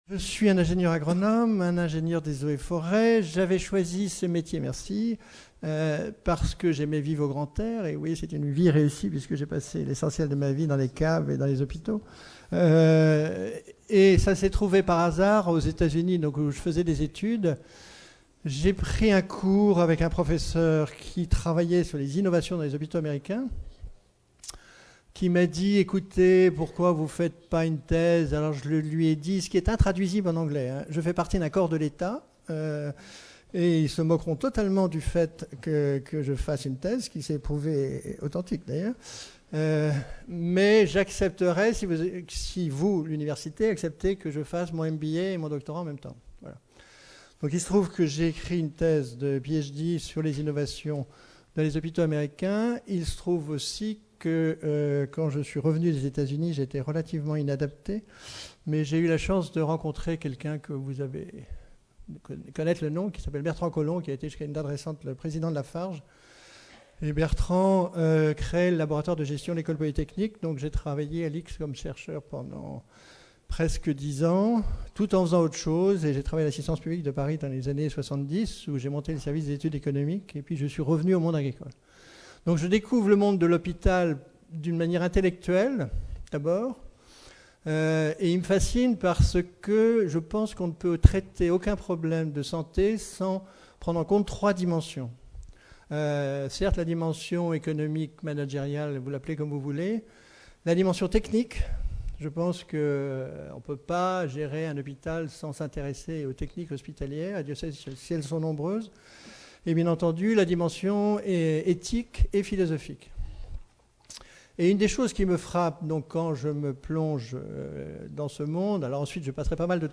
Conférence Invitée : Histoire du PMSI - Jean de Kervasdoue | Canal U
Congrès ADELF-EMOIS 2010